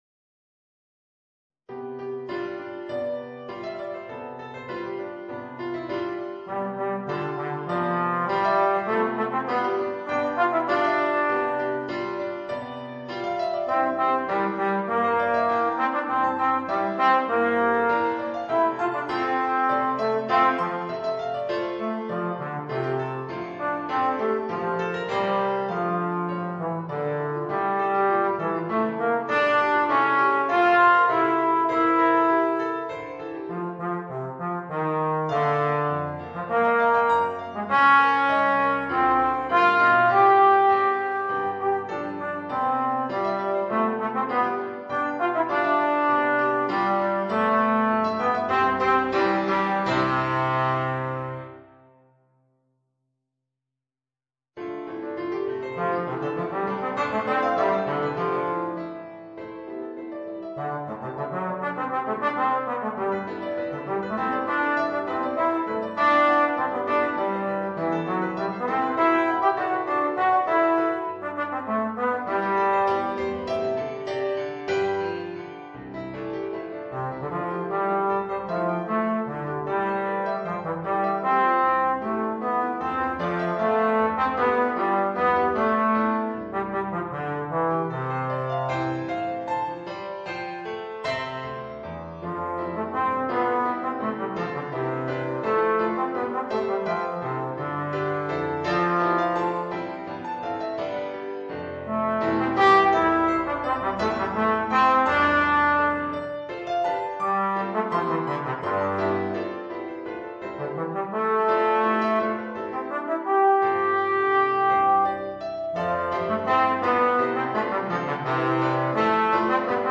Voicing: Trombone and Piano